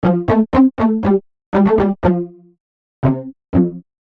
标签： 120 bpm Electronic Loops Synth Loops 689.23 KB wav Key : Unknown
声道立体声